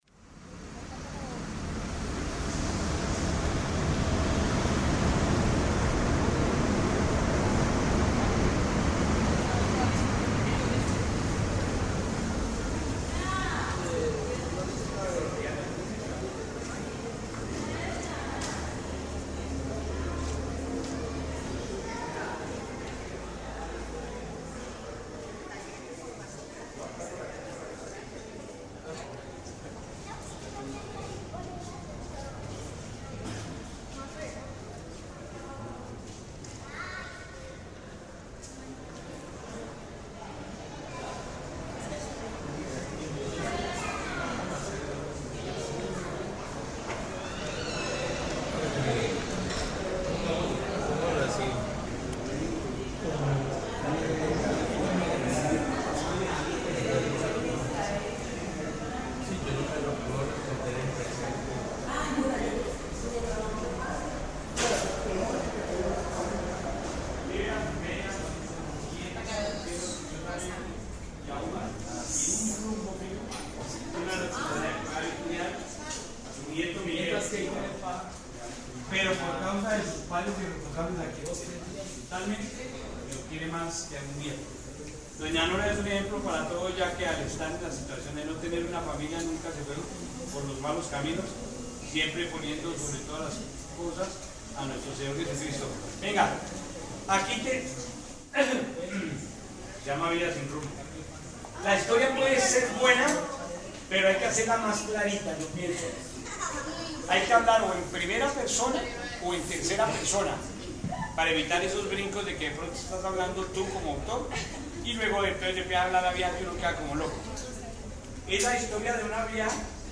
Paisaje_biblioteca Dptal.mp3
Registro sonoro del espacio de la cultura y el estudio. Recoge un recorrido desde el exterior en la transitada avenida donde está situado, hasta sus salas de lectura.